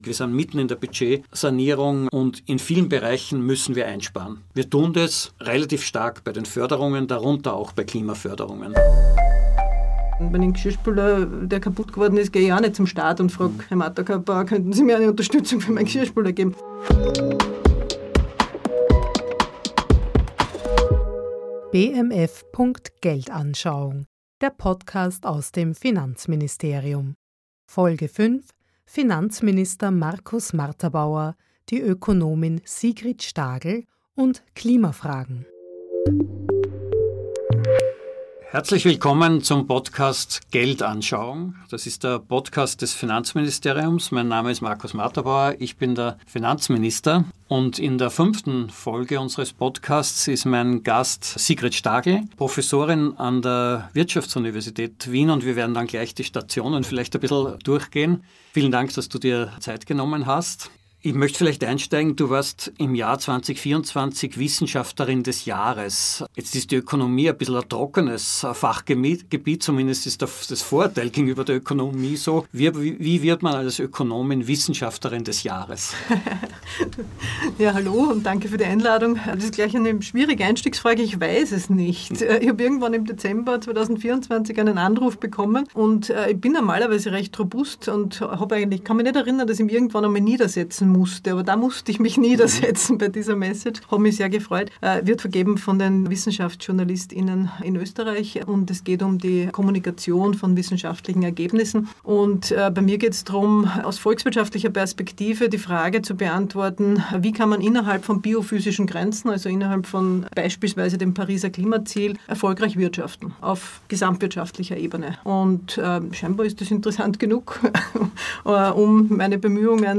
Wie kann Klimapolitik in Zeiten von knappen Budgets gelingen? Ein Gespräch über ökologische Ökonomie, systemische Effizienz, Förderungen und wie das Leben für alle besser werden kann.